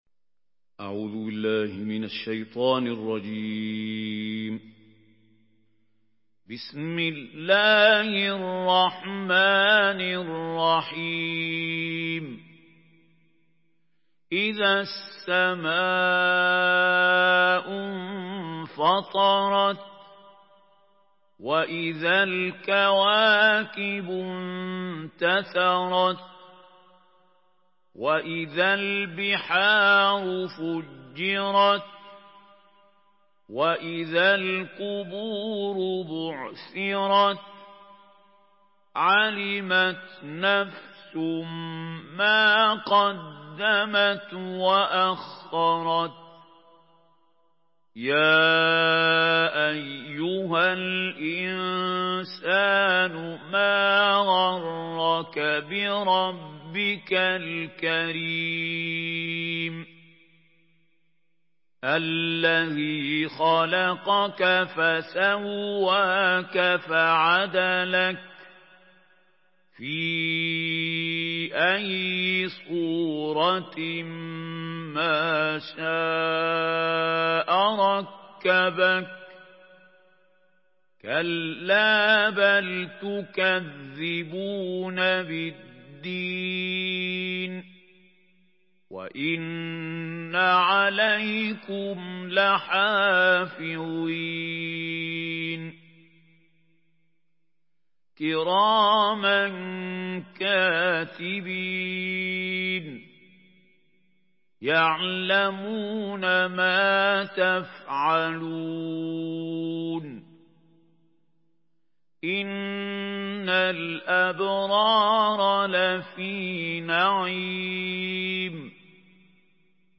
سورة الانفطار MP3 بصوت محمود خليل الحصري برواية حفص
مرتل